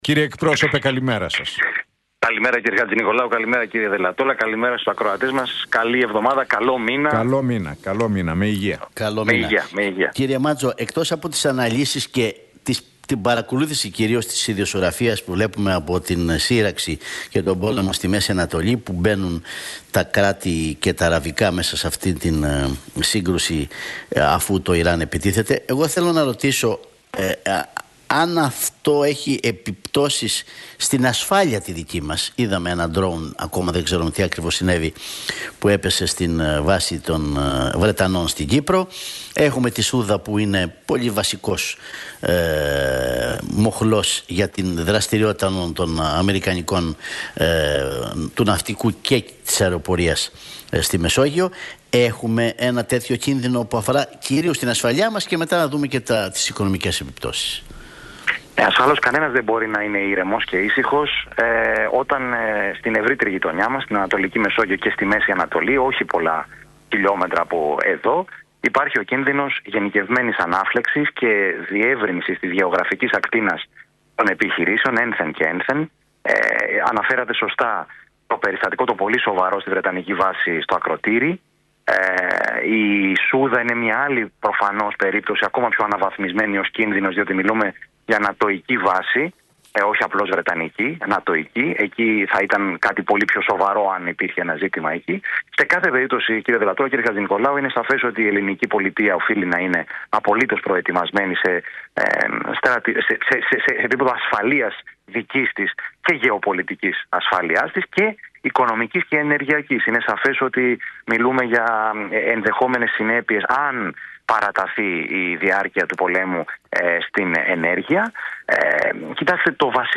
Μάντζος στον Realfm 97,8: Δεν πρέπει να υπάρξει γενίκευση της σύρραξης που να εμπλέκει το ΝΑΤΟ – Θα ήταν εφιαλτικό σενάριο και για την Ελλάδα